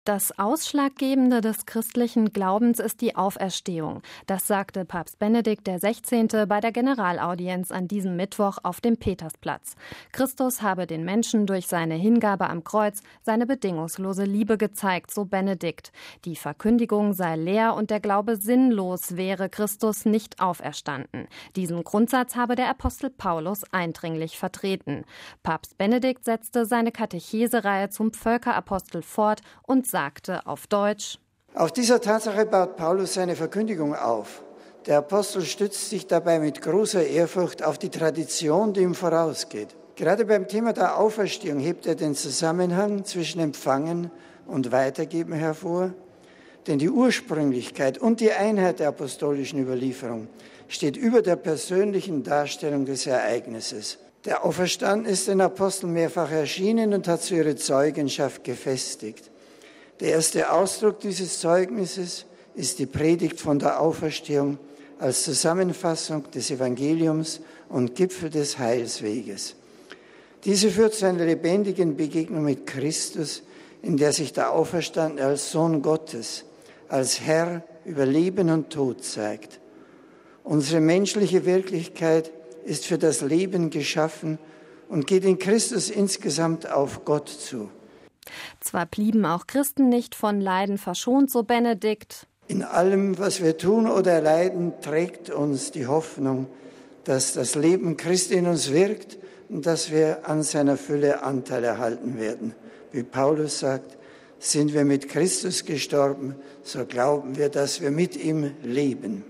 Das sagte Papst Benedikt der XVI. bei der Generalaudienz an diesem Mittwoch auf dem Petersplatz.
Papst Benedikt setzte seine Katechesereihe zum Völkerapostel fort und sagte auf Deutsch: